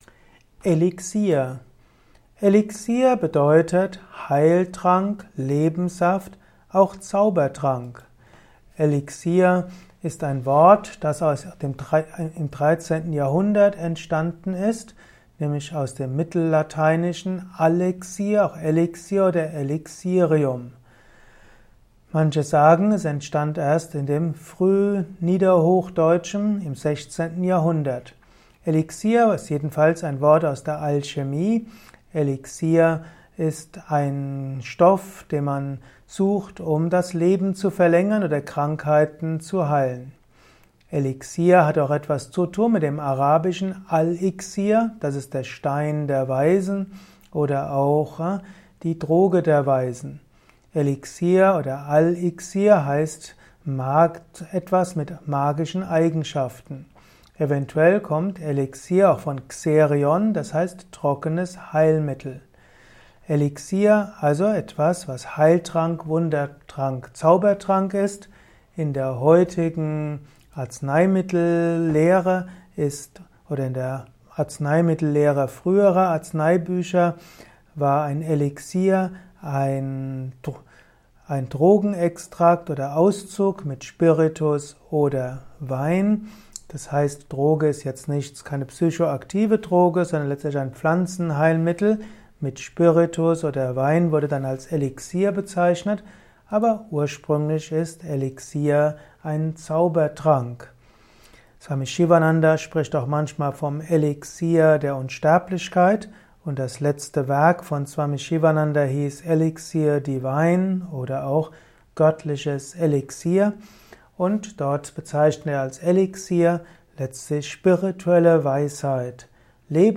Ein Kurzvortrag zu dem Begriff Elixier